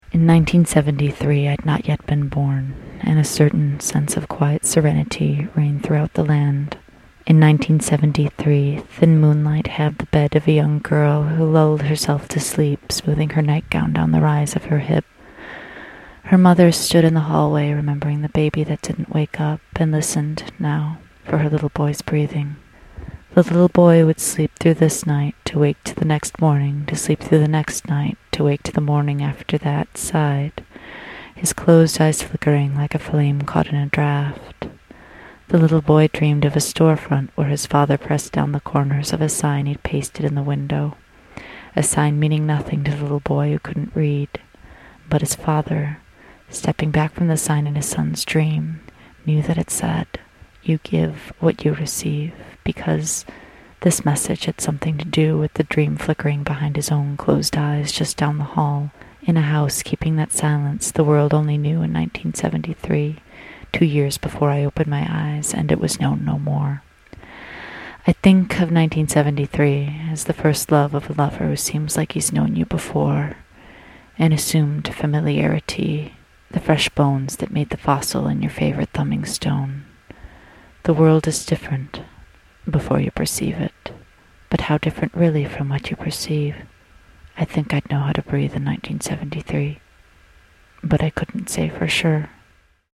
Short Form Storytelling